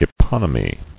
[e·pon·y·my]